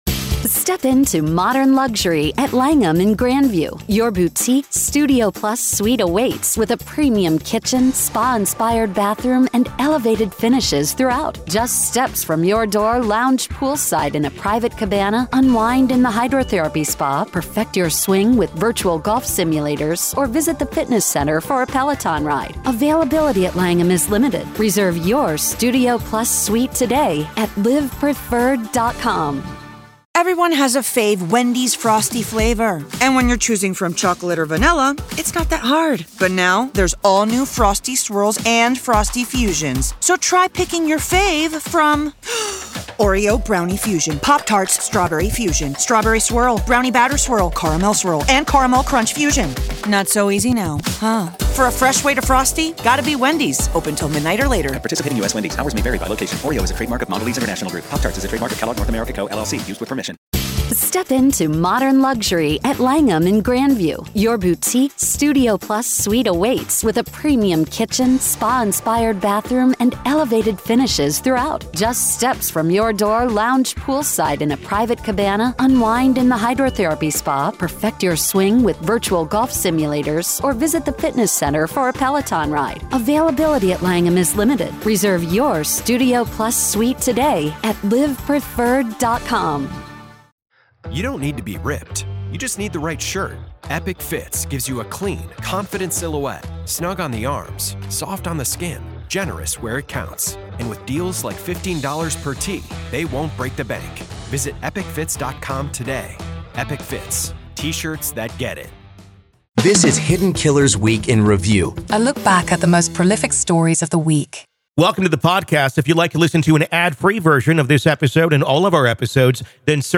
From high-profile criminal trials to in-depth examinations of ongoing investigations, this podcast takes listeners on a fascinating journey through the world of true crime and current events. Each episode navigates through multiple stories, illuminating their details with factual reporting, expert commentary, and engaging conversation.